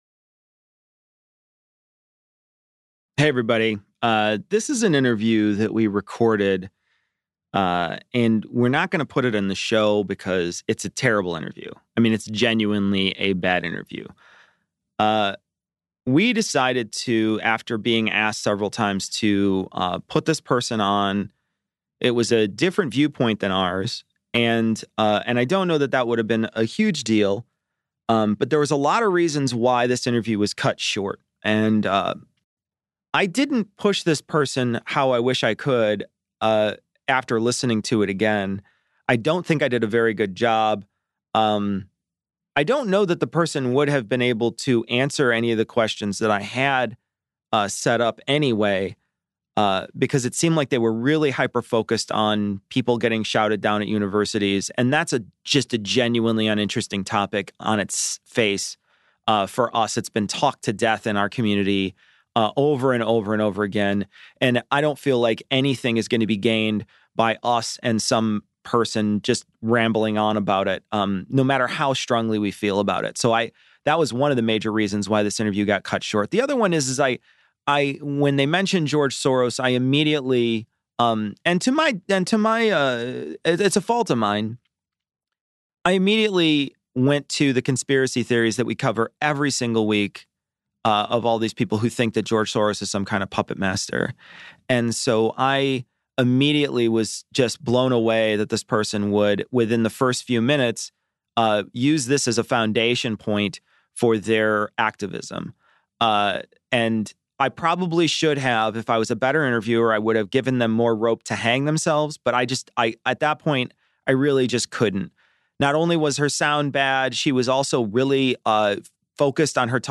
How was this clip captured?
We interview a republican, We last 15 minutes. It's a horrible bit of tape.